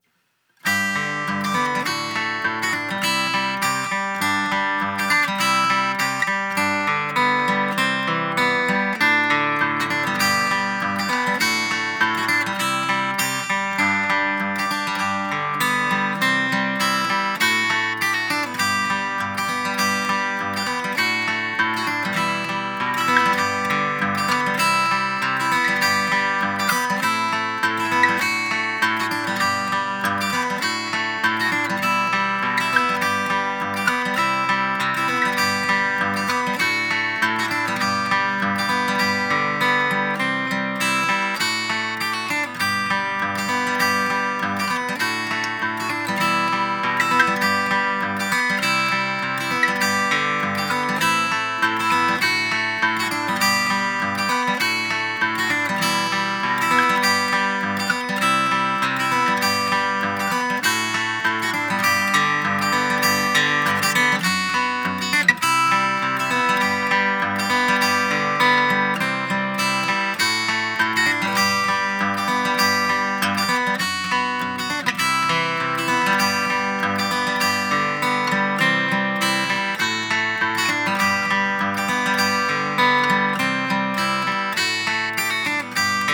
acousticguitar_1073EQ-Ahpf.flac